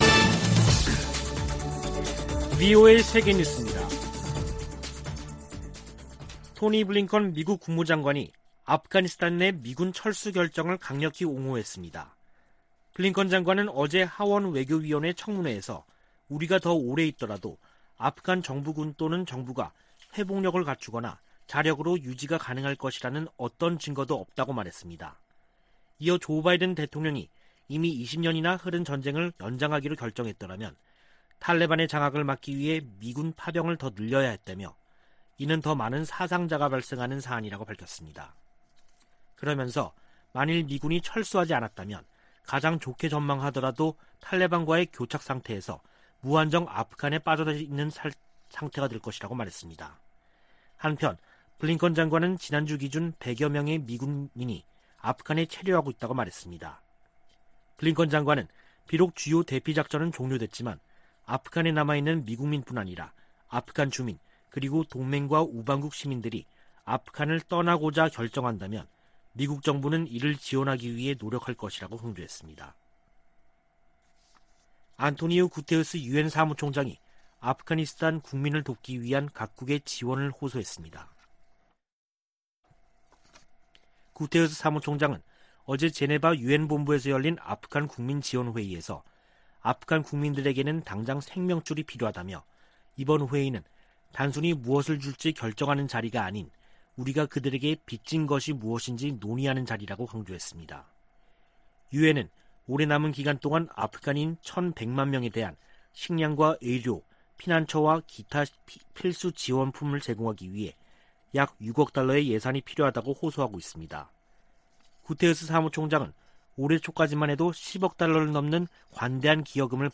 VOA 한국어 간판 뉴스 프로그램 '뉴스 투데이', 2021년 9월 14일 3부 방송입니다. 성 김 미국 대북특별대표는 미국은 북한에 적대적 의도가 없다며 북한이 대화 제의에 호응할 것을 거듭 촉구했습니다. 북한의 신형 장거리 순항미사일 시험발사는 도발이라기 보다는 무기체계 강화의 일환이라고 미국의 전문가들이 평가했습니다. 북한의 미사일 발사는 주민들의 식량을 뺏는 행위라고 국제인권단체가 비판했습니다.